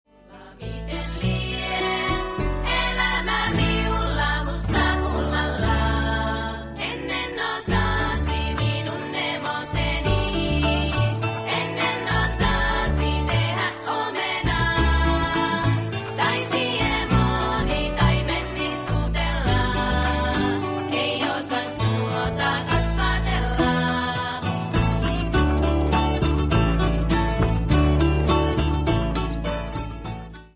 A very nice slow song